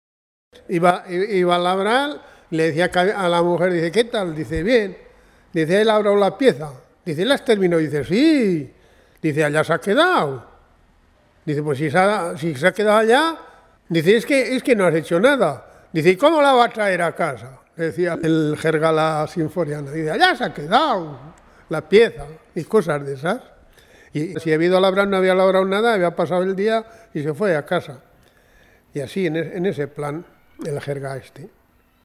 Clasificación: Cuentos